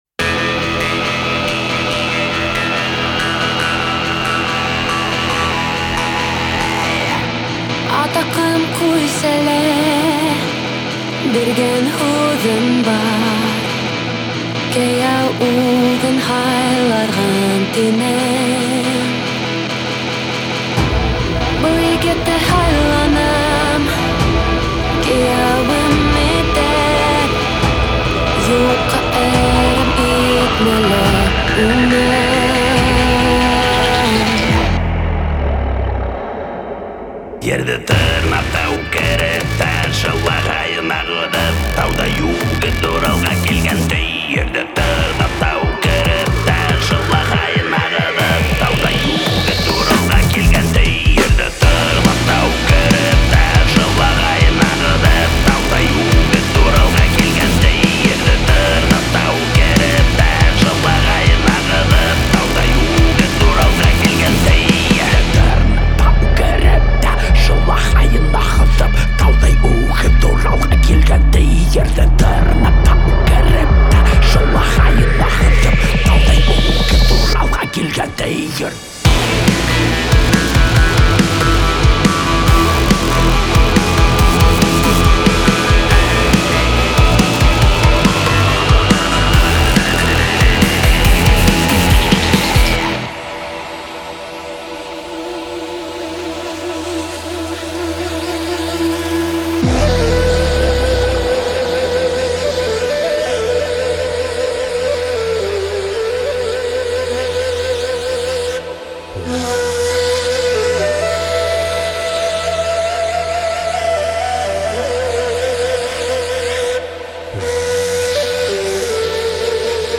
Качество: 320 kbps, stereo
Башкортостан музыка